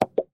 Звук при надавливании на присоску для лучшего прилипания